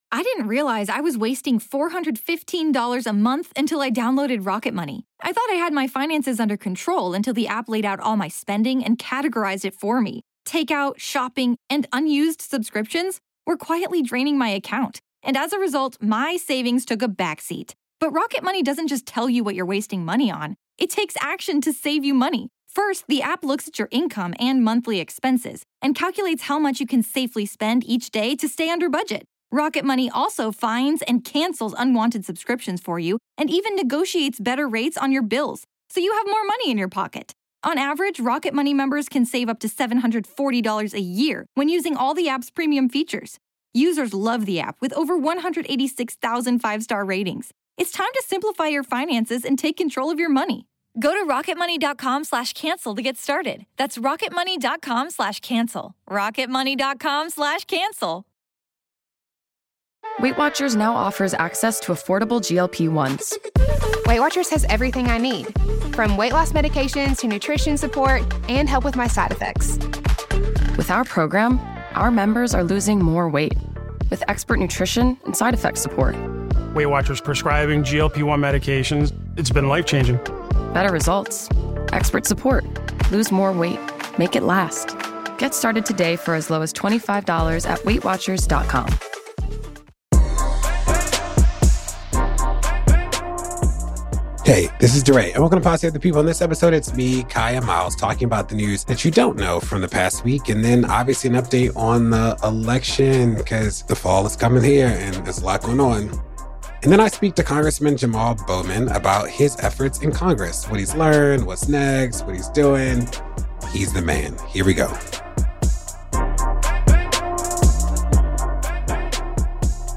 Damning messages from a far-right group chat, Trump becomes first U.S. president convicted of crimes, a discussion on discriminatory housing practices, and the grand return of a lost holiday predating Juneteenth. DeRay interviews Rep. Jamaal Bowman (D-NY 16th District) about life as a Congressman, efforts with the Hip Hop Task Force, and more.